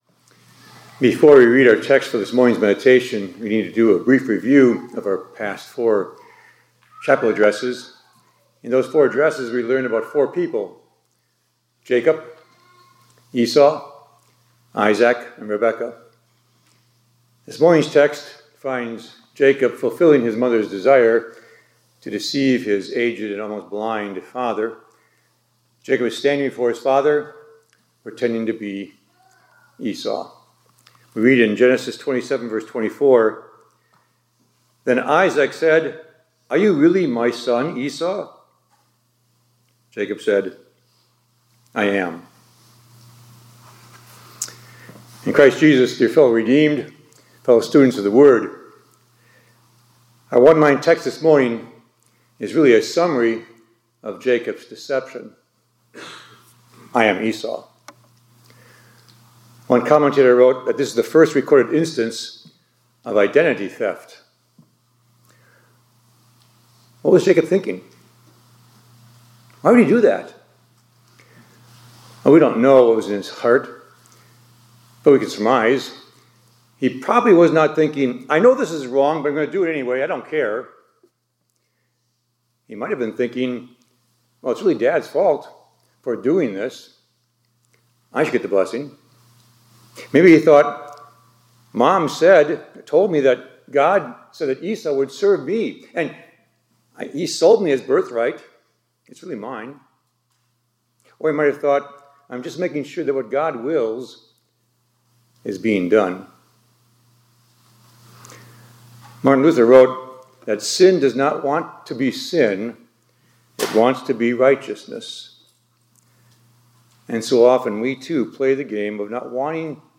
2025-01-20 ILC Chapel — God Wants Us to Take Off the Mask